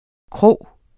Udtale [ ˈkʁɔˀw ]